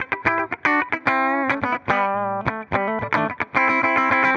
Index of /musicradar/dusty-funk-samples/Guitar/110bpm